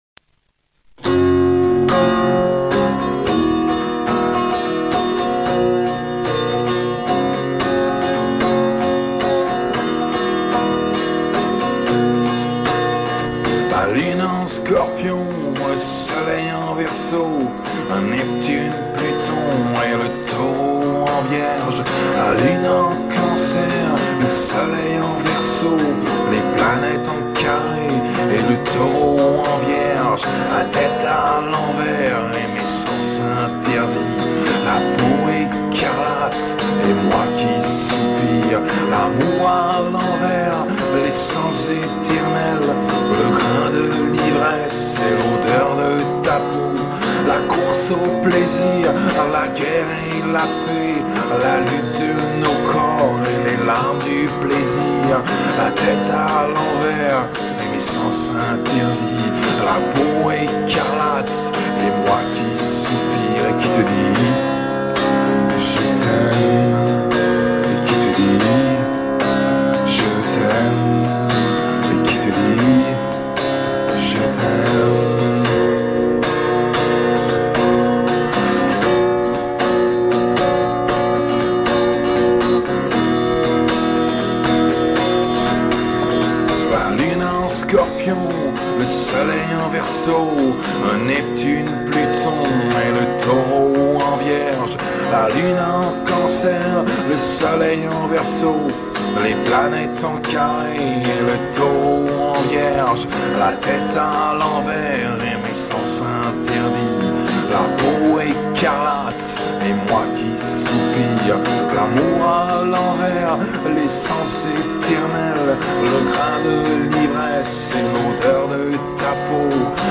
rythm guitar + acoustic guitars